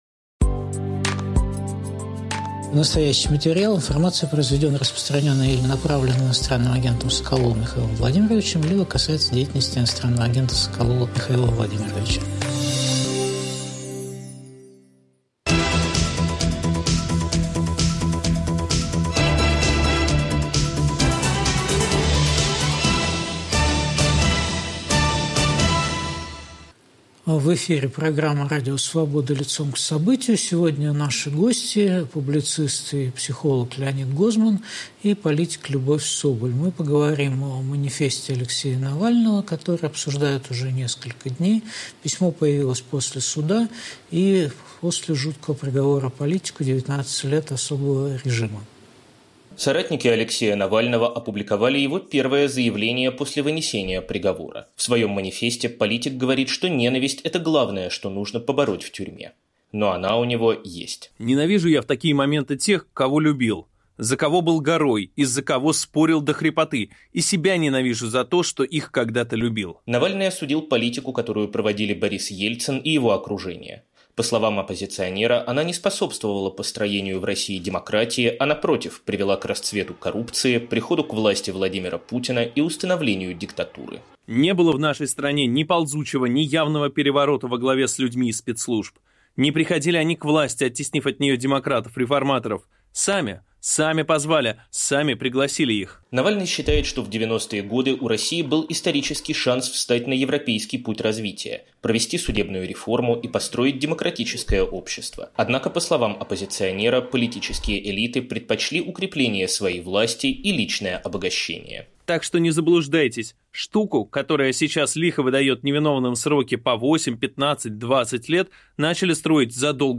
Споры о манифесте Алексея Навального. В эфире Леонид Гозман, Любовь Соболь.